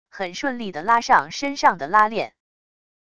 很顺利地拉上身上的拉链wav音频